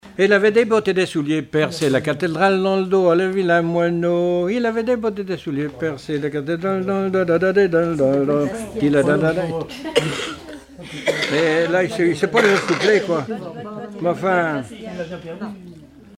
danse : polka
Répertoire de chansons populaires et traditionnelles
Pièce musicale inédite